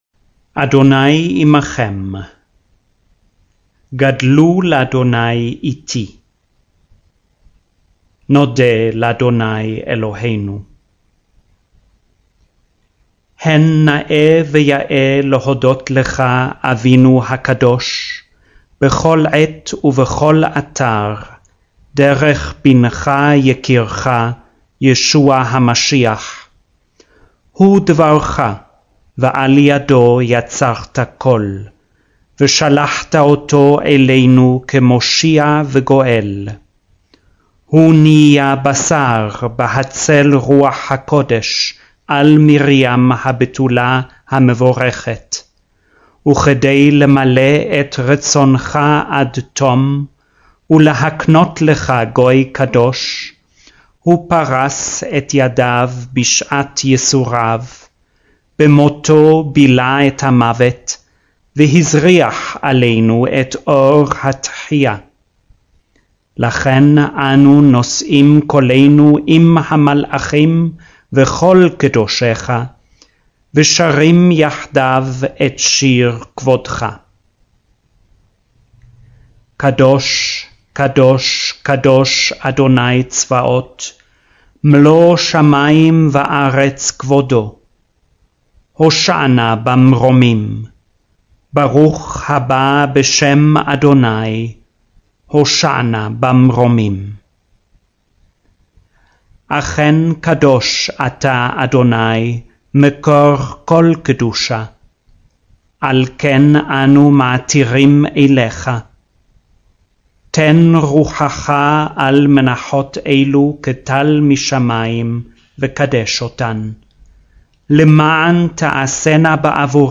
il testo della liturgia cattolica romana letta lentamente in ebraico
07- Eucharistic Prayer 2.mp3